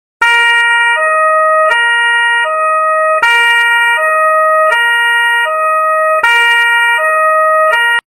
Suoneria Sirena Carabinieri
Categoria Effetti Sonori